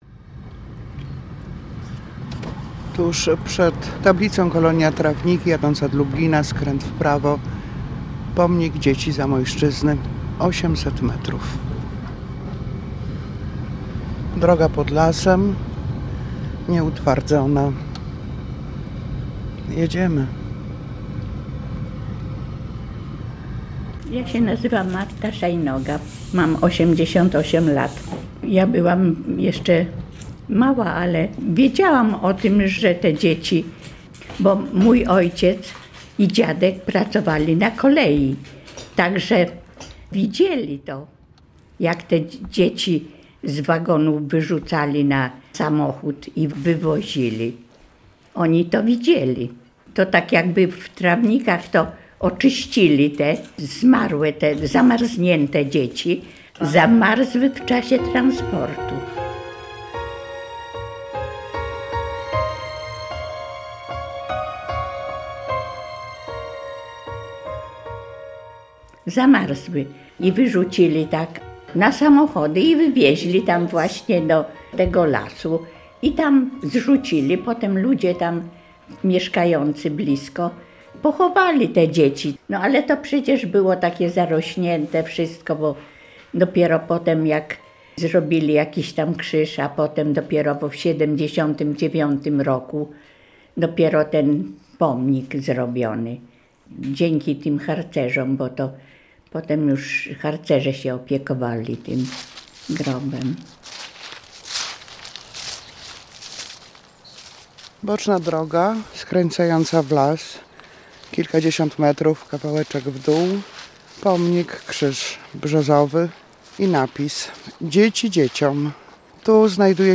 Tagi: reportaż